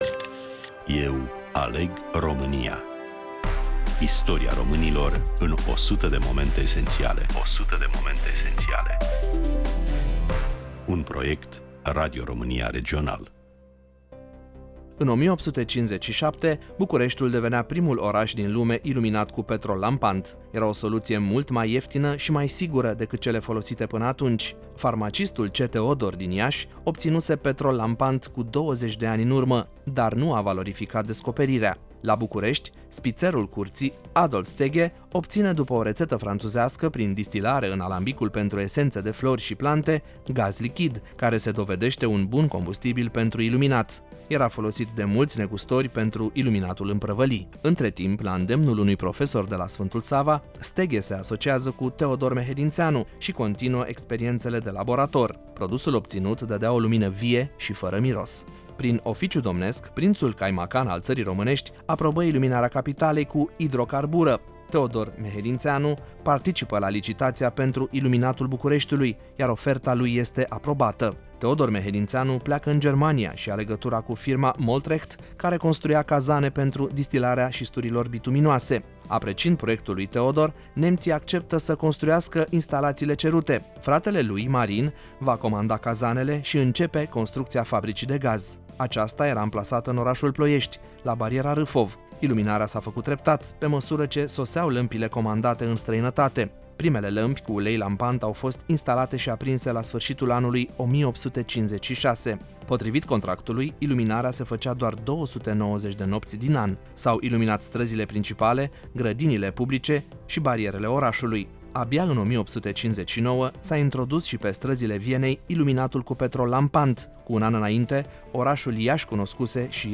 Prezentator / voice over